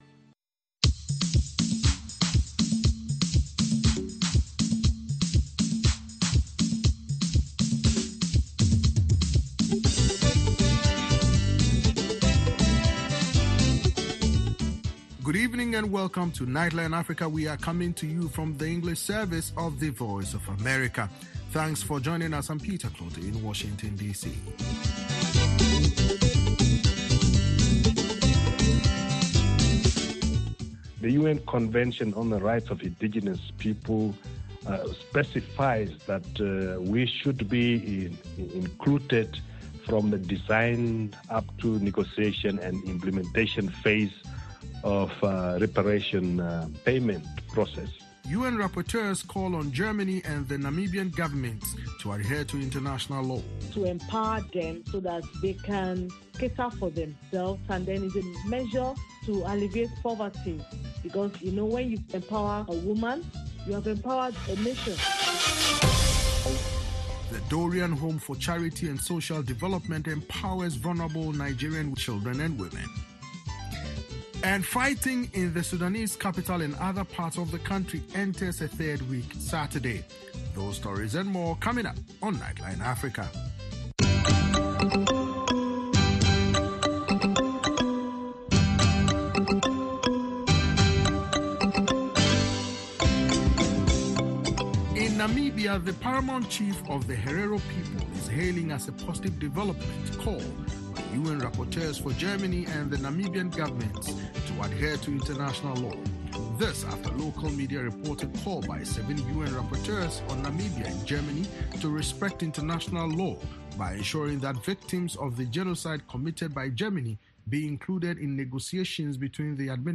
On Nightline Africa: The Governor of Nigeria’s Edo State, Godwin Obaseki, recently sat down with VOA to discuss the Basic Education Sector Transformation Project focused on using public resources to boost learning in the west African nation. Plus, fighting in Sudan entered its third week despite several cease-fire agreements.